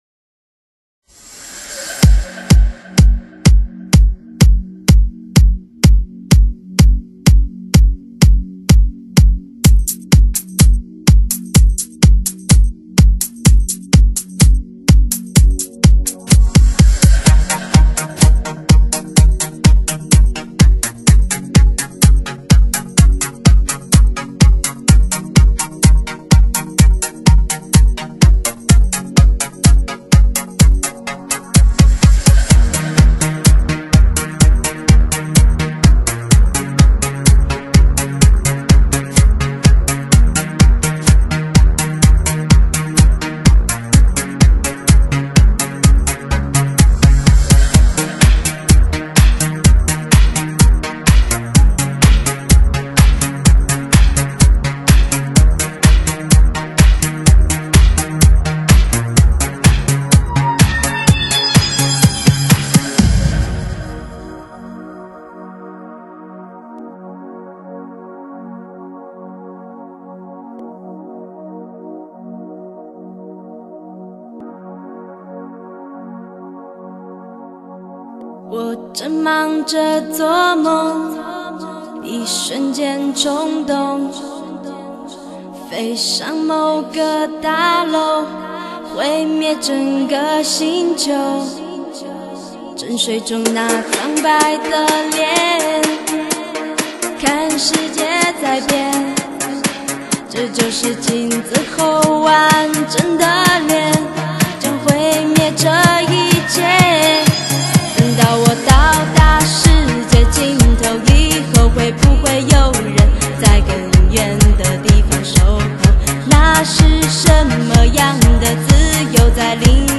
CD for high closs car audio testing